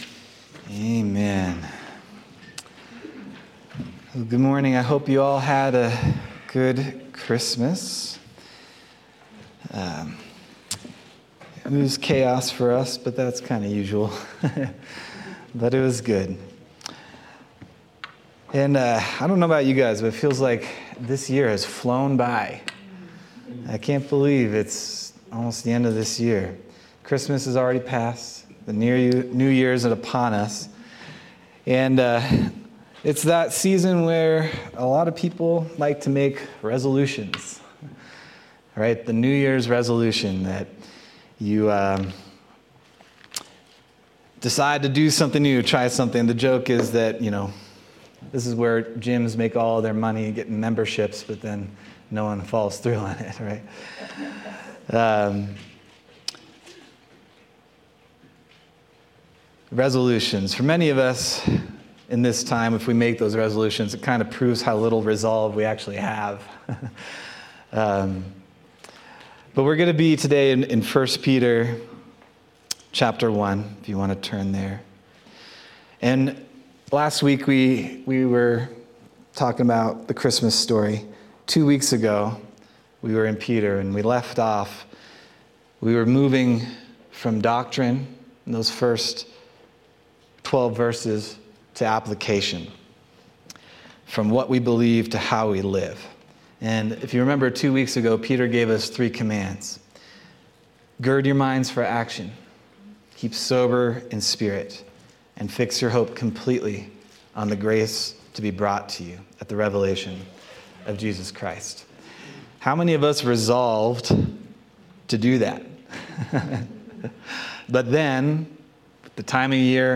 December 29th, 2024 Sermon